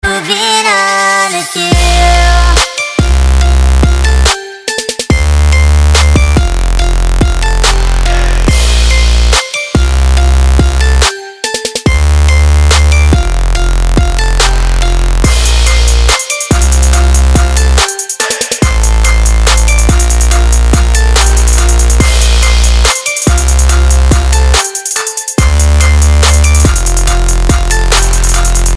DubStep
Метки: красивые, Electronic, future bass,